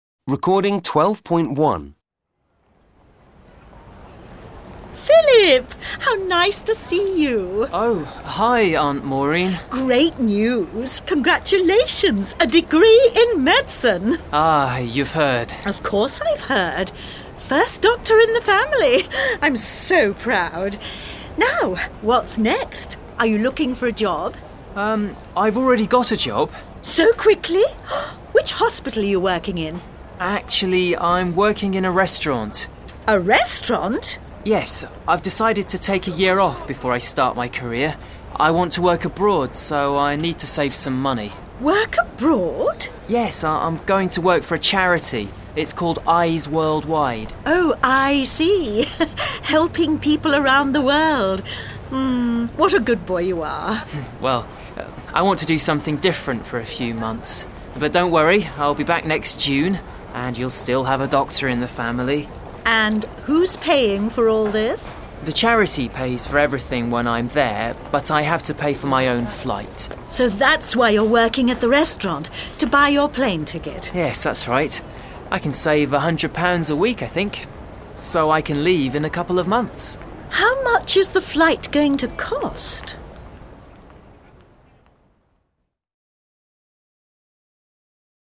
conversation_A.mp3